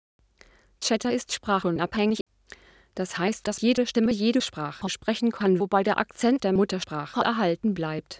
CHATR's German synthesis)